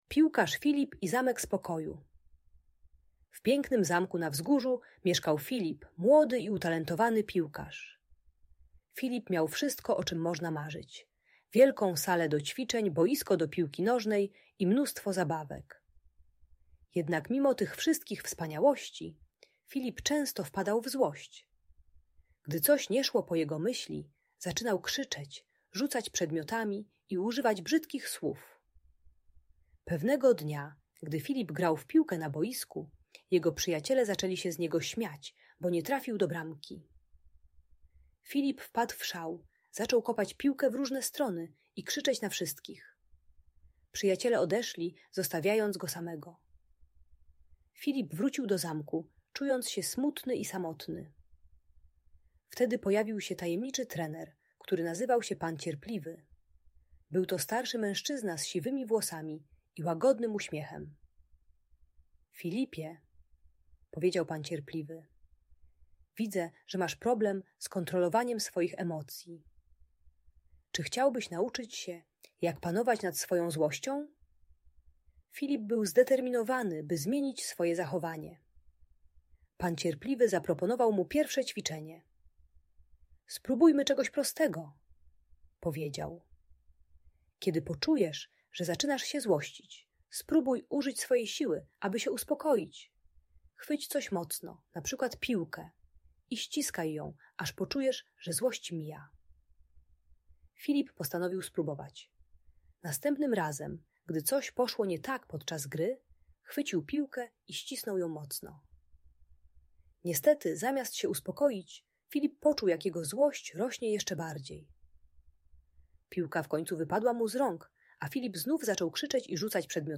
Piłkarz Filip i Zamek Spokoju - Bunt i wybuchy złości | Audiobajka
Uczy techniki wizualizacji spokojnego miejsca - dziecko zamyka oczy i wyobraża sobie bezpieczną przestrzeń, gdy narasta frustracja. Darmowa audiobajka o radzeniu sobie ze złością.